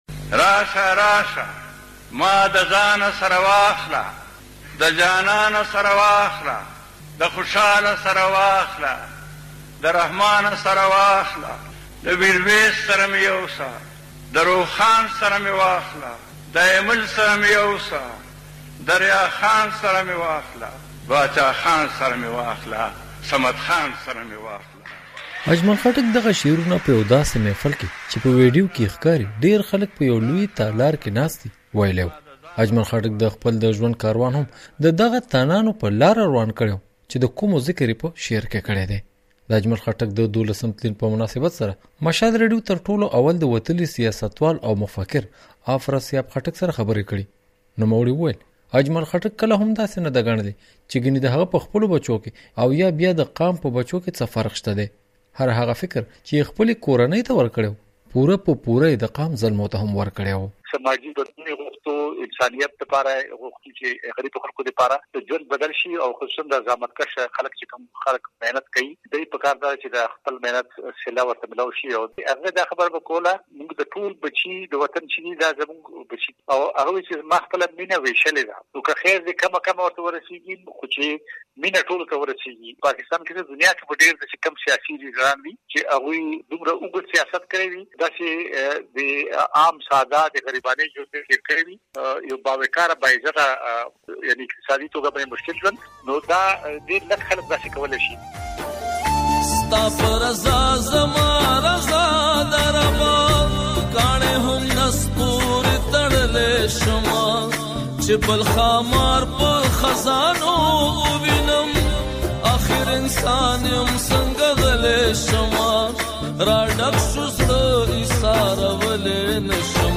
د خټک د ژوند او کار په اړه تفصیلي رپورټ د غږ په ځای کې واورئ.